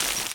glass_i2.wav